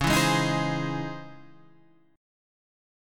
C# Minor Major 9th